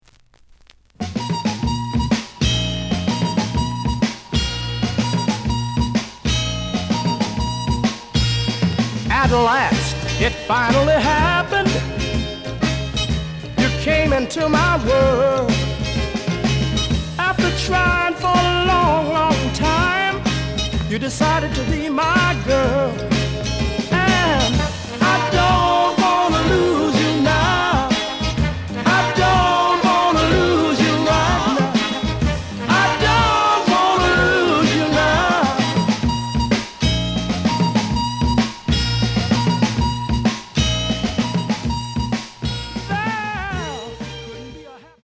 The listen sample is recorded from the actual item.
●Genre: Soul, 60's Soul
●Record Grading: VG~VG+ (傷はあるが、プレイはおおむね良好。Plays good.)